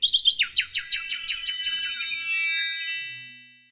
nightingale_morph.mp3